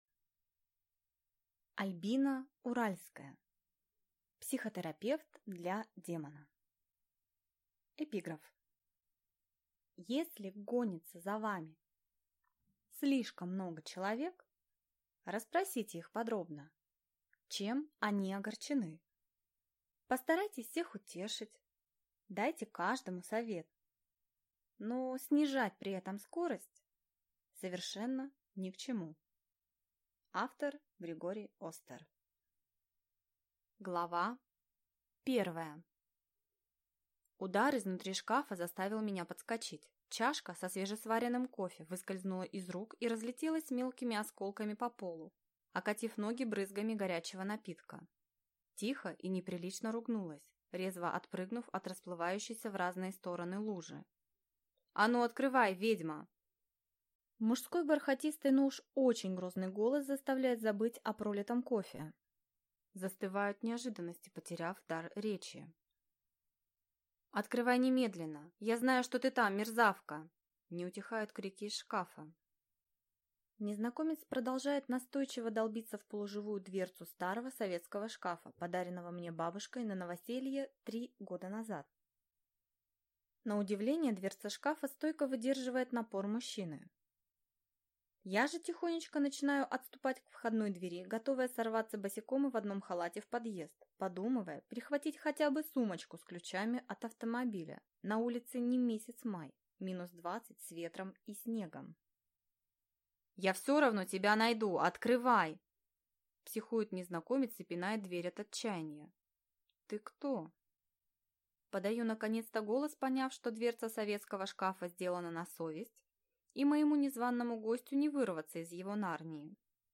Аудиокнига Психотерапевт для демона | Библиотека аудиокниг